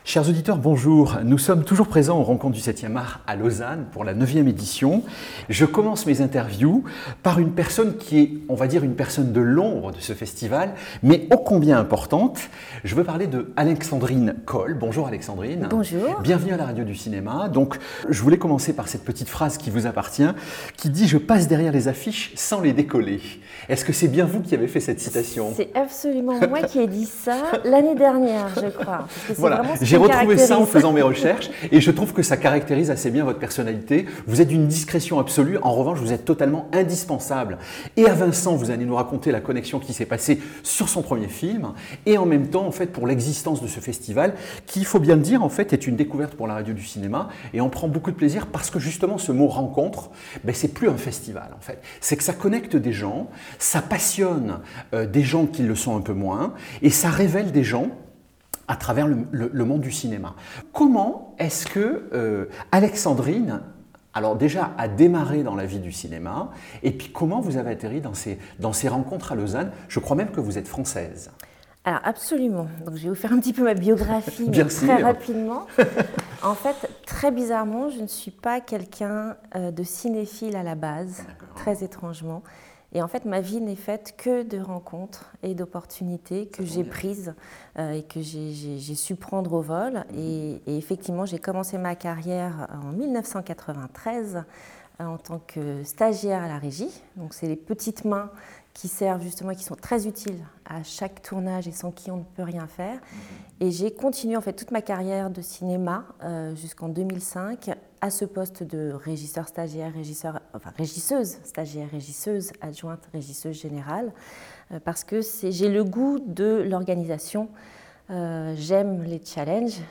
Festival • Lausanne • coulisses